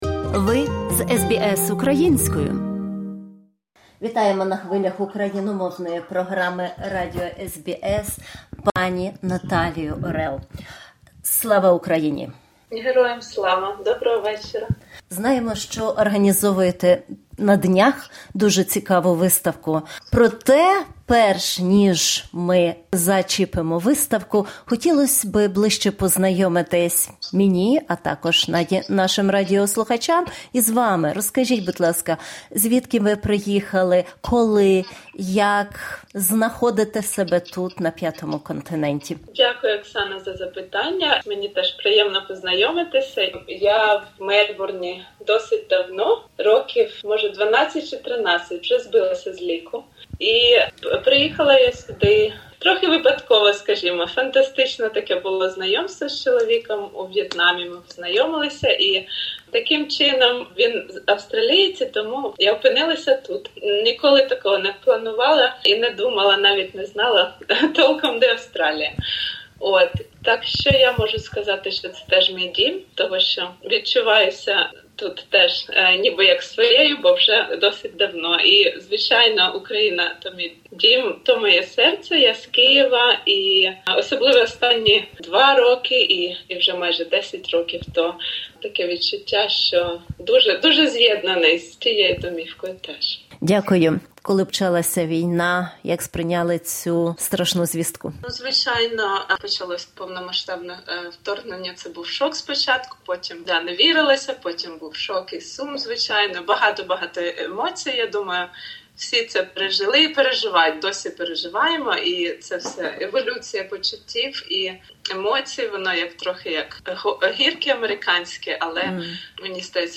Bridging Cultures Through Art: An Interview